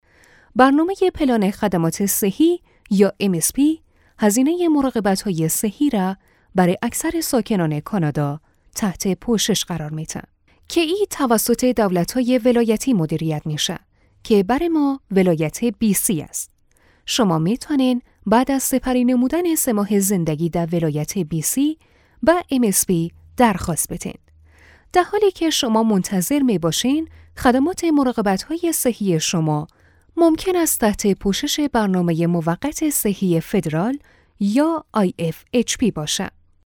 Female